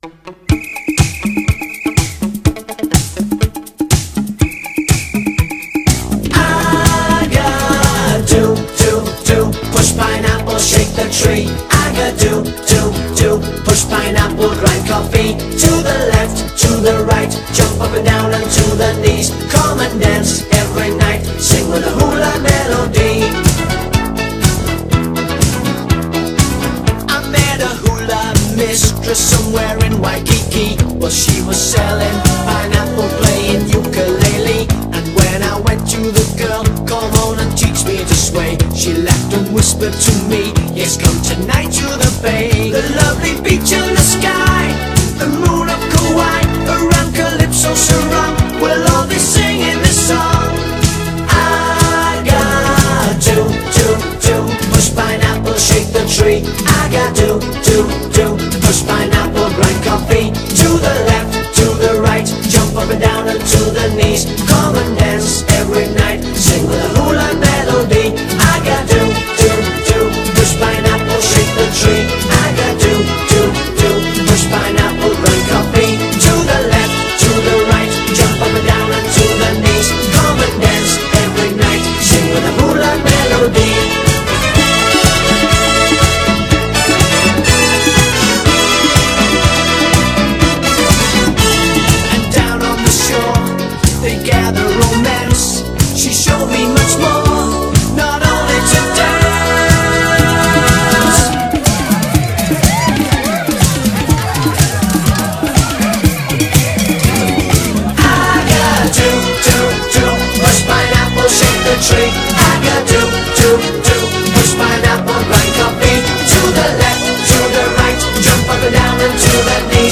BPM123
MP3 QualityMusic Cut